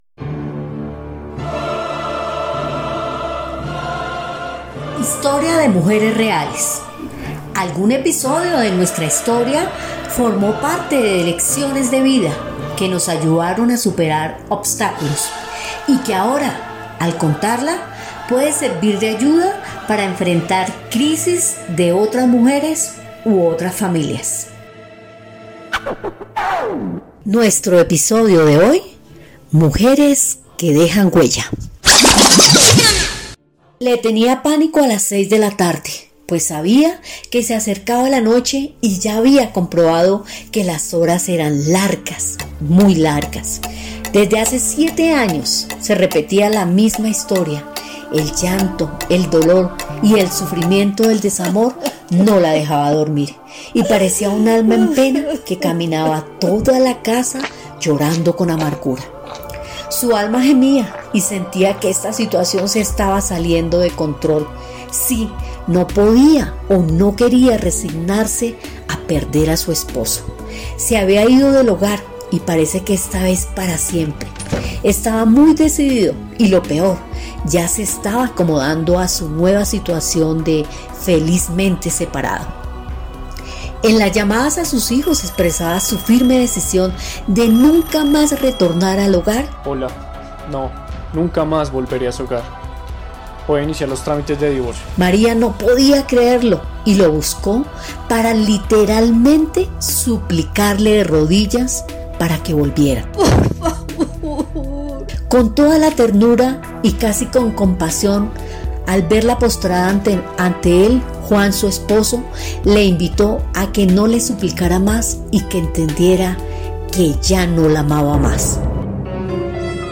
Programa "Historias de mujeres reales" : taller de radio en la Biblioteca Púbica Carlos E. Restrepo
Contó con la participación intergeneracional de usuarios de la mencionada biblioteca.
Producción radial, Divorcio